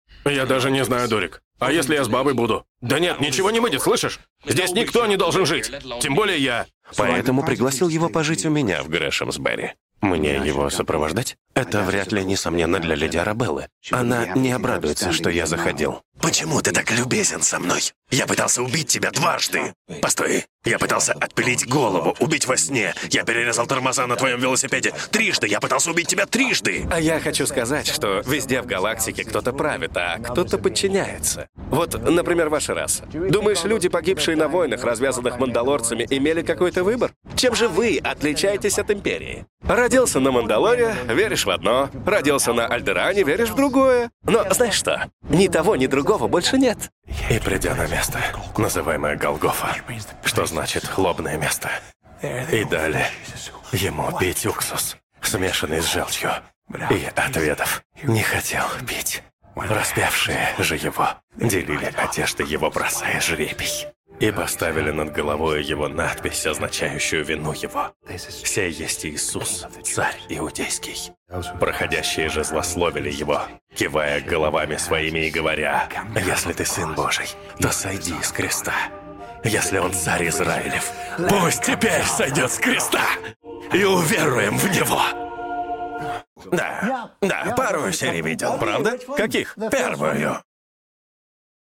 Закадр
Муж, Закадровый текст